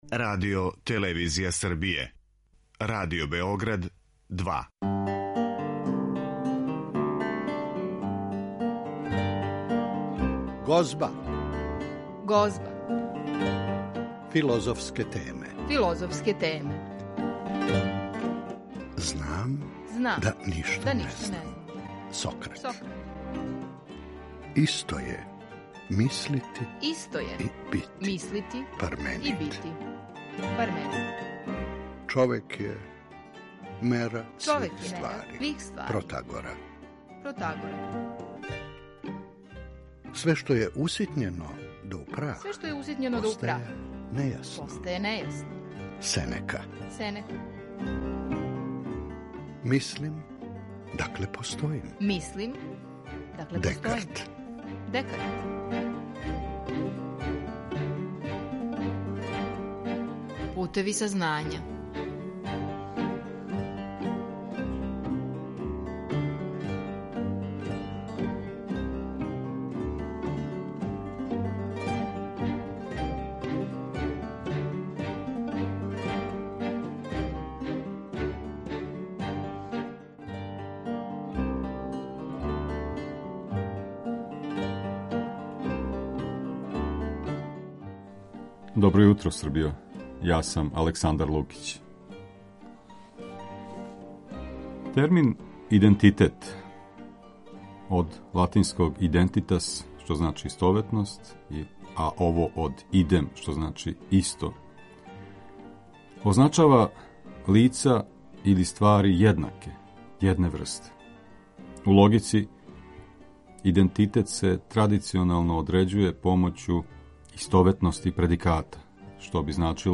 Емисија се бави филозофским темама